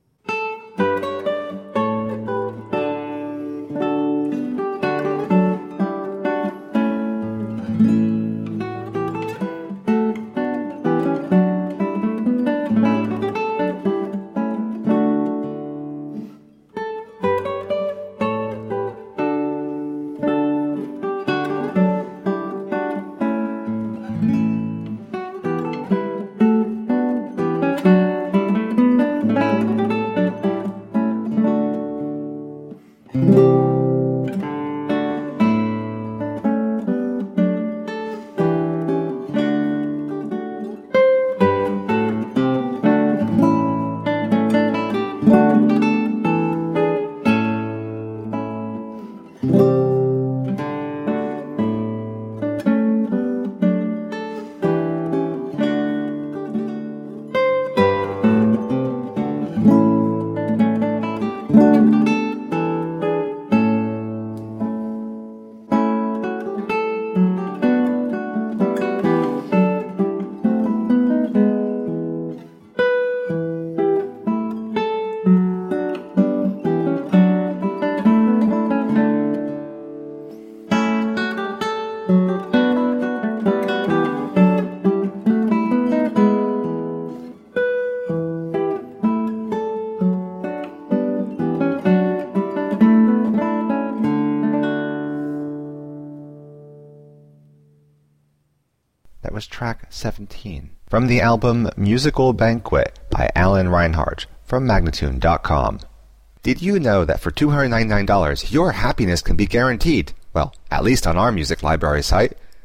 A feast of renaissance and baroque music.
Classical, Renaissance, Instrumental
Lute